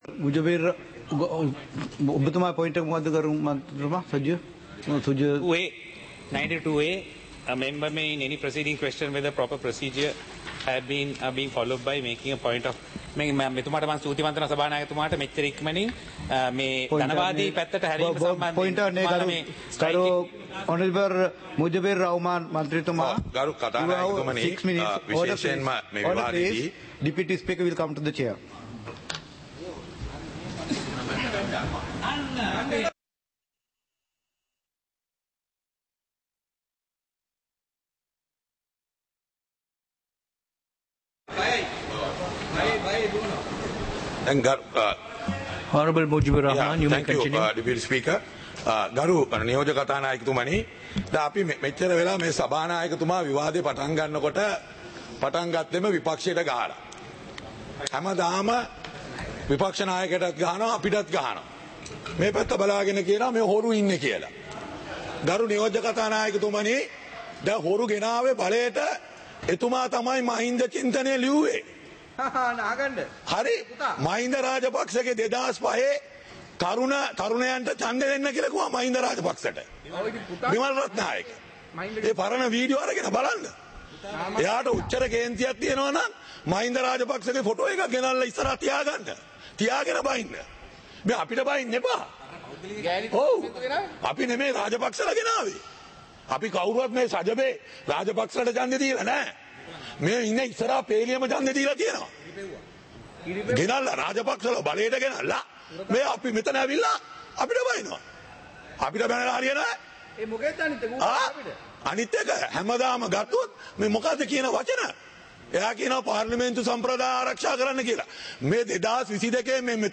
சபை நடவடிக்கைமுறை (2026-02-18)
நேரலை - பதிவுருத்தப்பட்ட